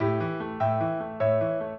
piano
minuet10-9.wav